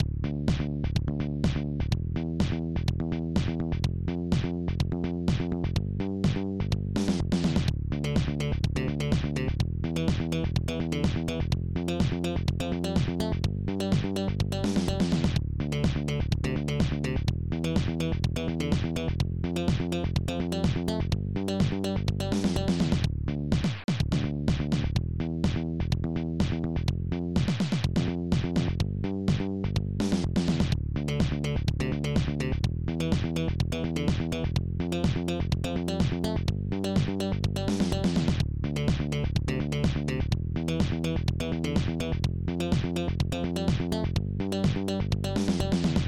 Protracker Module
elecbass filckbass